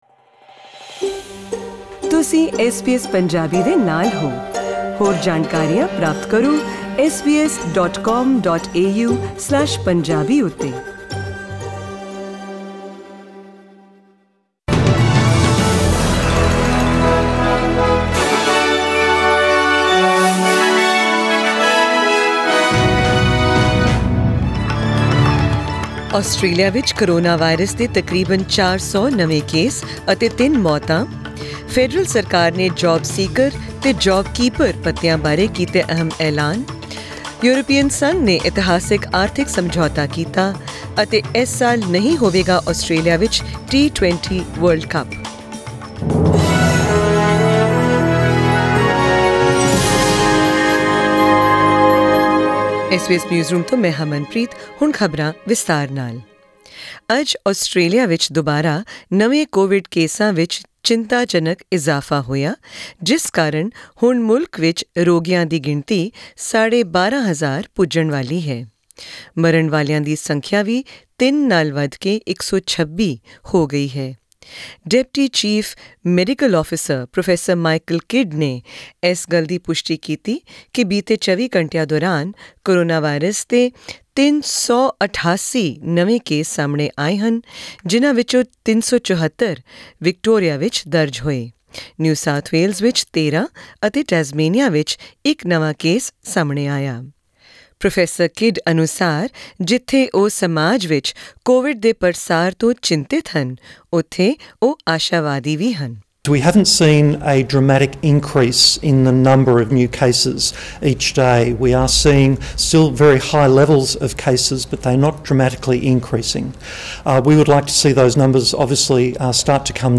In tonight's SBS Punjabi bulletin, hear the latest news from Australia and beyond, including sports, currency details, and tomorrow's weather forecast.